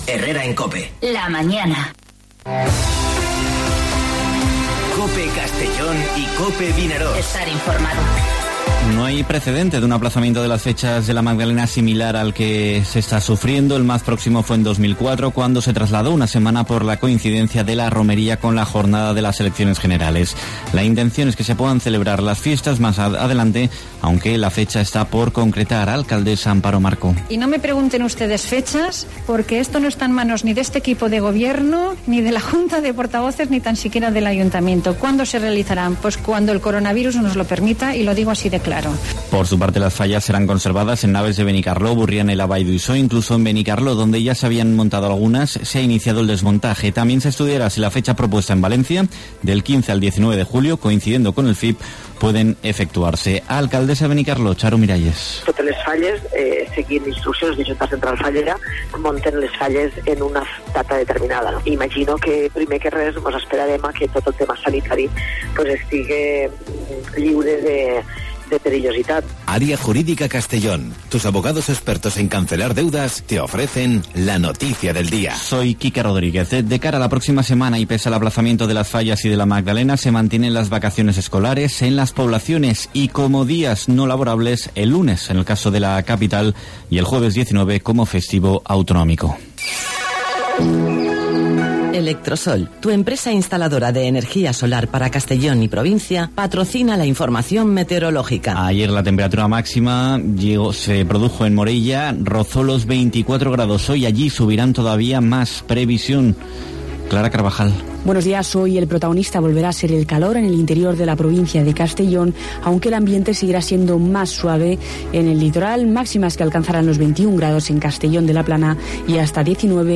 Informativo Herrera en COPE Castellón (12/03/2020)